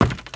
STEPS Wood, Creaky, Run 11.wav